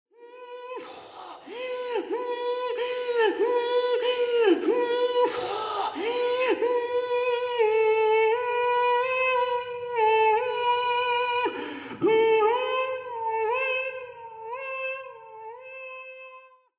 kreise (2004) for voice solo >> more